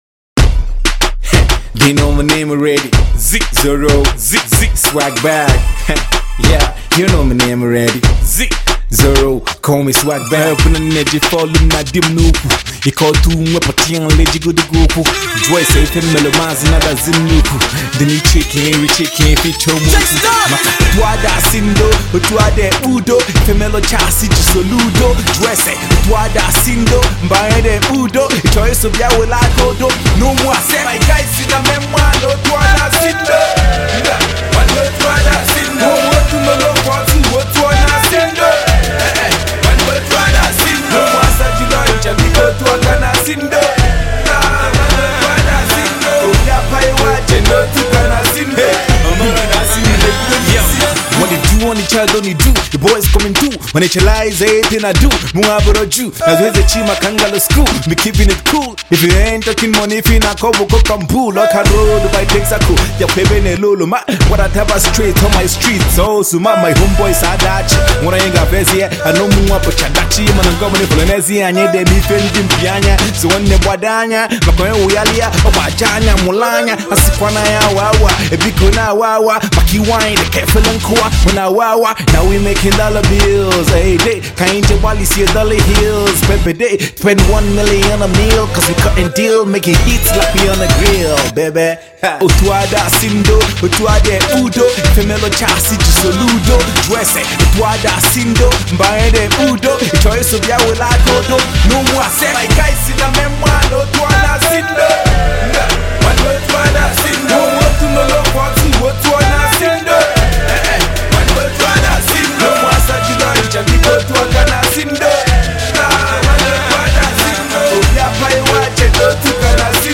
completely hardcore
Straight HipHop.